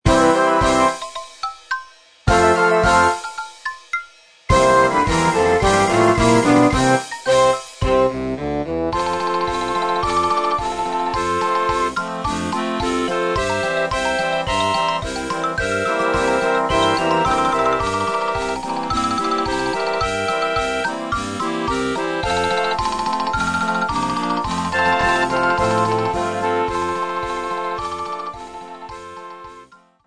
Ksylofon solo z towarzyszeniem orkiestry